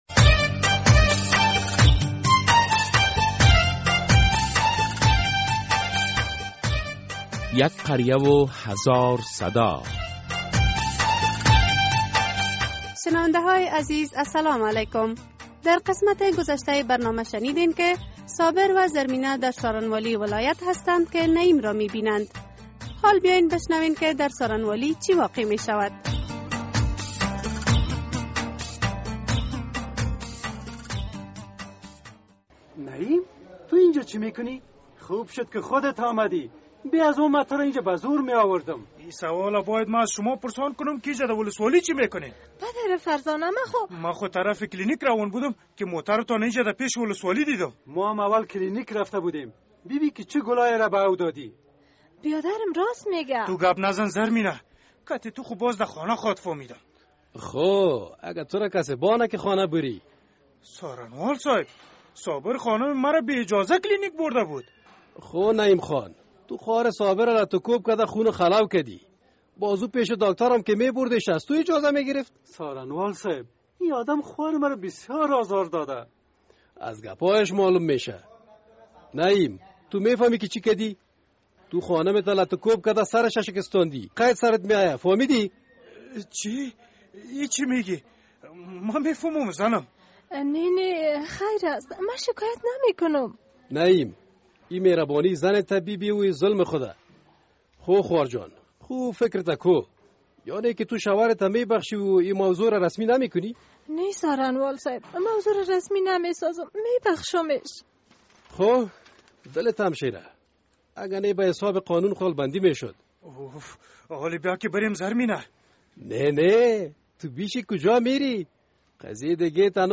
در این درامه که موضوعات مختلف مدنی، دینی، اخلاقی، اجتماعی و حقوقی بیان می گردد هر هفته به روز های دوشنبه ساعت ۳:۳۰ عصر از رادیو آزادی نشر می گردد...